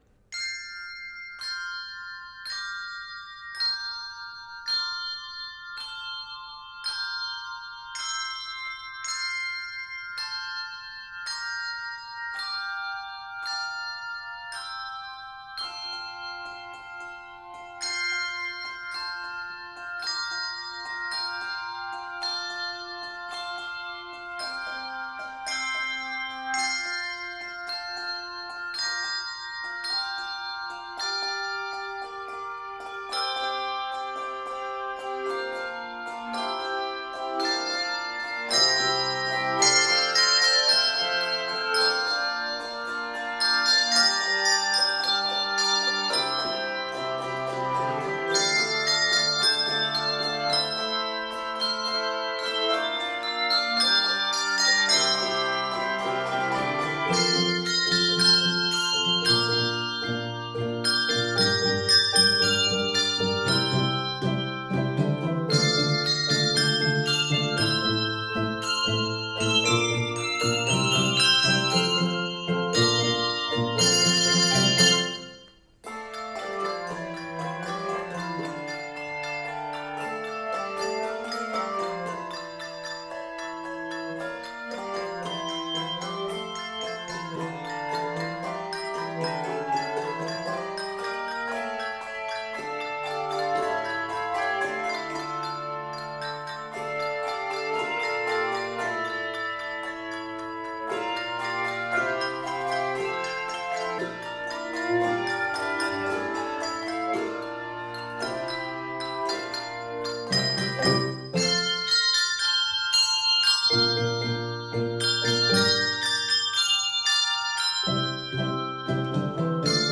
An opening peal gives way to an energetic melody
Keys of F Major and d minor.
N/A Octaves: 3-6 Level